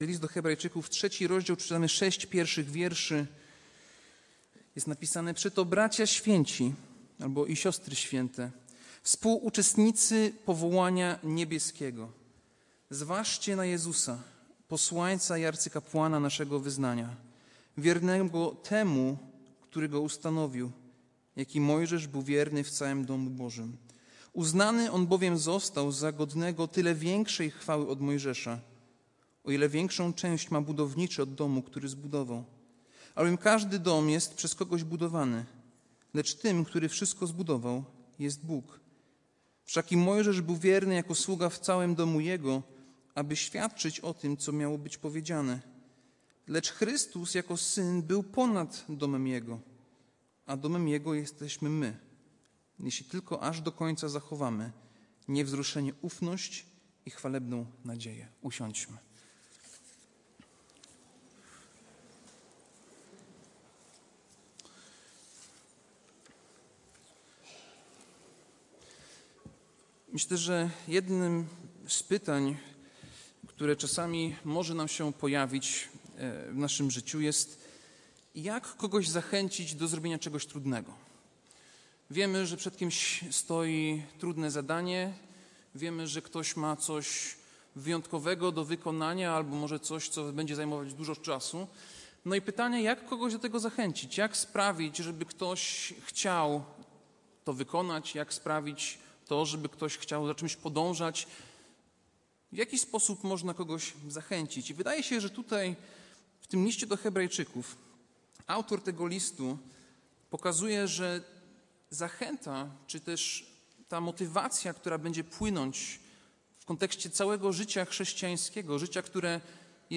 Passage: List do Hebrajczyków 3, 1-6 Kazanie